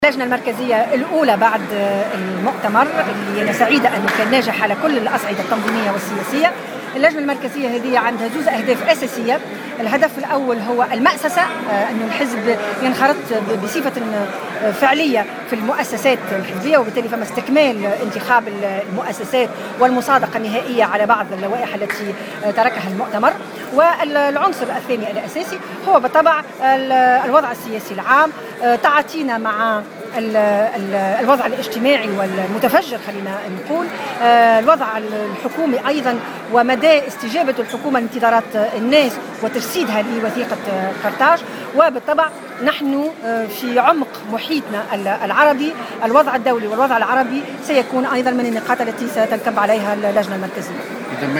وصفت القيادية بالحزب الجمهوري مية الجريبي اليوم السبت الوضع الاجتماعي في تونس بالمتفجّر، في تصريحات صحفية اليوم على هامش انعقاد اللجنة المركزية في المنستير.